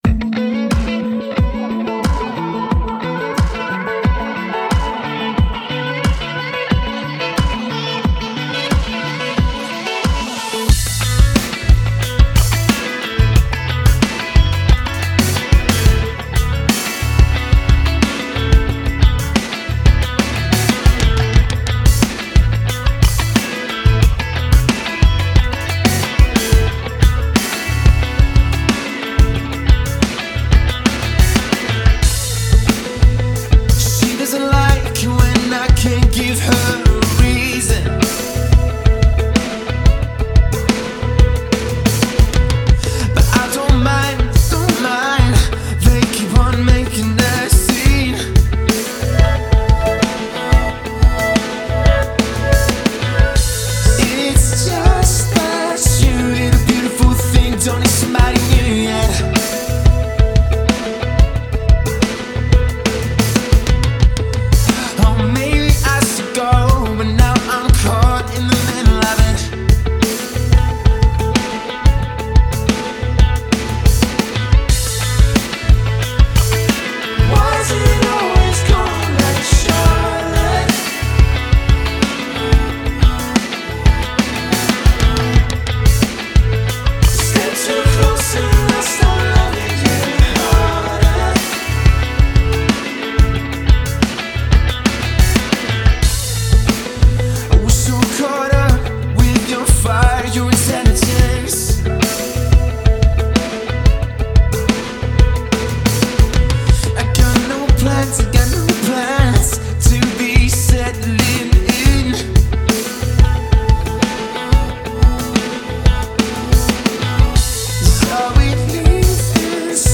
Pop/Rock
modern pop-indie/rock music.